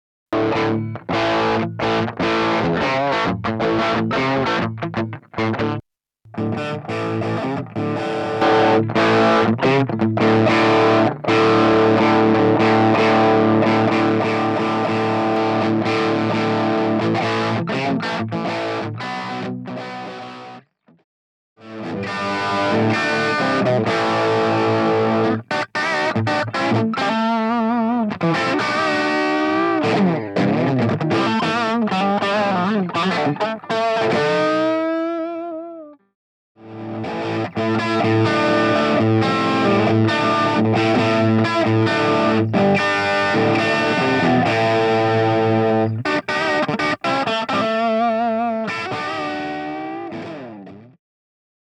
test de mon son chez moi à l'arrache (je faisais le tour de mes differents grain d'ampli sans me soucier de ce que je jouais,et je devrai parce que je joue comme un manche :/ ) , mais bon on peut entendre les micros strat'60
En tout cas très sympa ton son, ça donne envie d'en entendre plus.